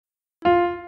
Нота Фа в музыке и ее особенности